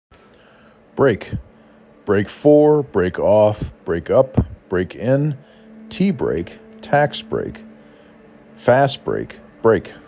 5 Letters, 1 Syllable
b r A k